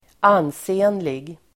Uttal: [²'an:se:nlig]